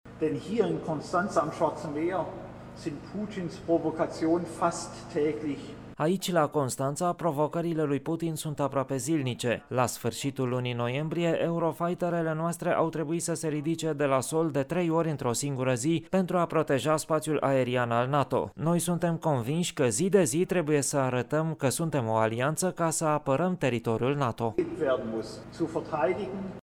Schmid a subliniat importanța alianței NATO: